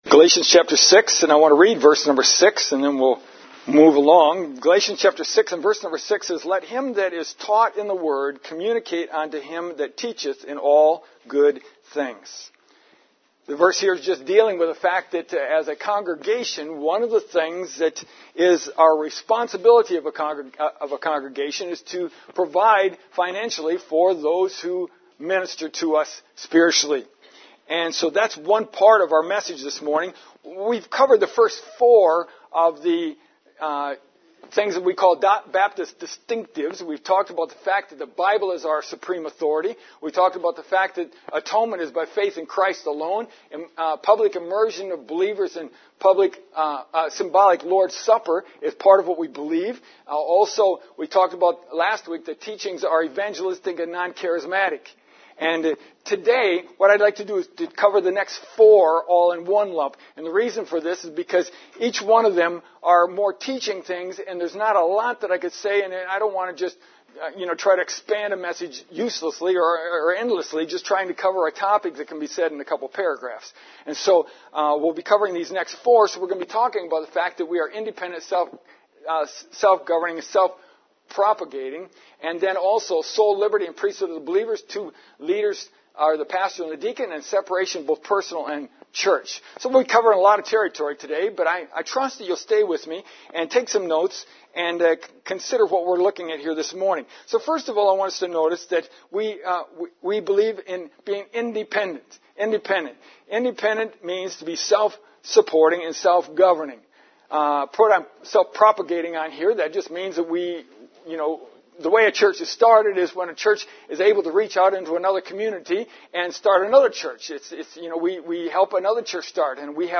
In the last four sermons, we examined the first four Baptist Distinctives.